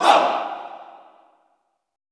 MALE OOAH -L.wav